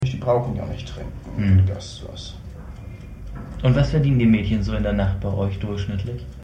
4.6 Unverständliches und schwer Verständliches